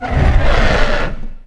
c_horisath_atk2.wav